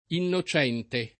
inno©$nte] agg. e s. m. — sim. il pers. m. e f. Innocente e i cogn. Innocente, -ti, Degl’Innocenti, Degli Innocenti — dal cogn., la locuz. m. pl. tubi Innocenti (tecn.): non tubi innocenti